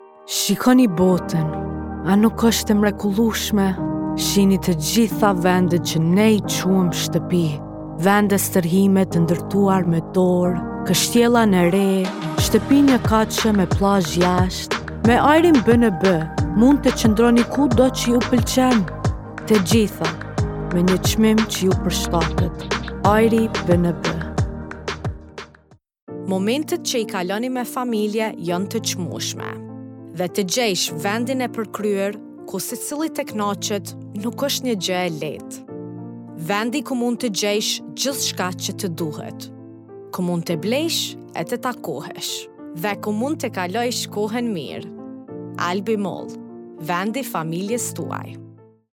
Albanian, Female, Home Studio, Teens-30s